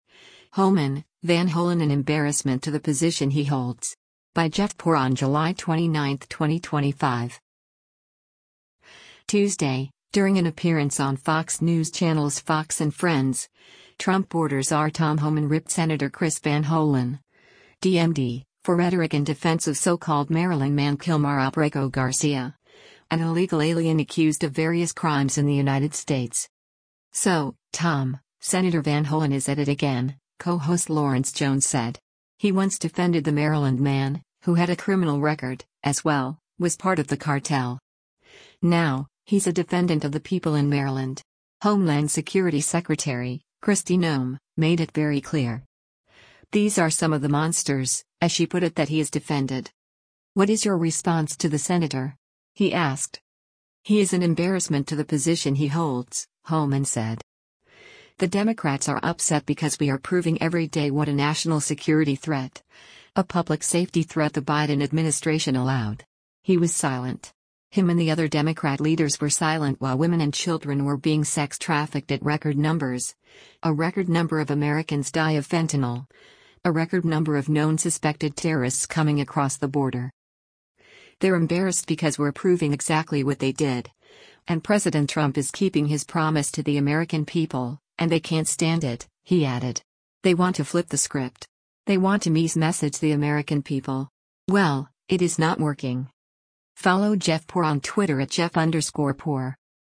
Tuesday, during an appearance on Fox News Channel’s “Fox & Friends,” Trump border czar Tom Homan ripped Sen. Chris Van Hollen (D-MD) for rhetoric and defense of so-called Maryland man Kilmar Abrego Garcia, an illegal alien accused of various crimes in the United States.